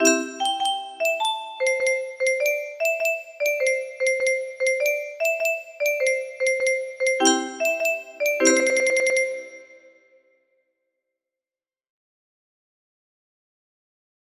M7-M10 music box melody